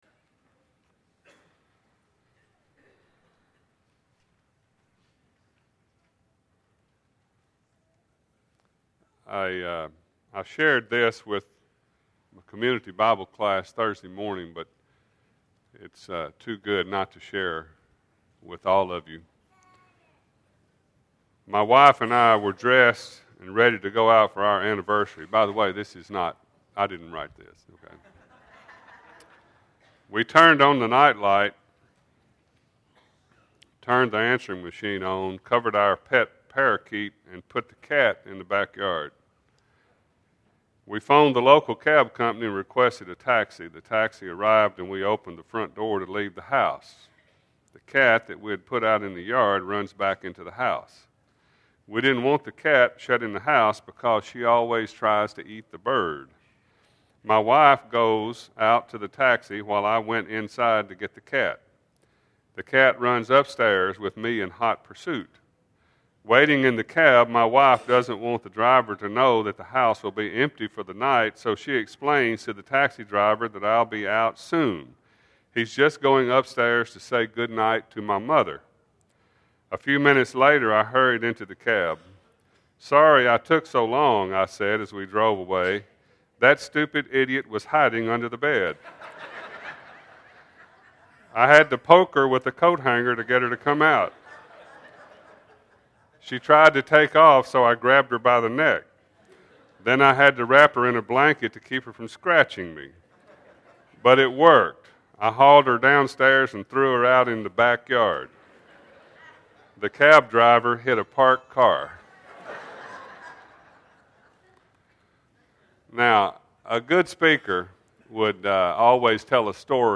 Processing Grief (1 of 2) – Bible Lesson Recording
Sunday AM Sermon